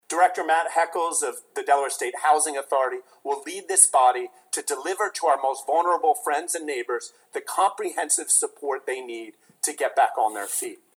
Governor Matt Meyer presented his State of the State Speech this afternoon in Dover.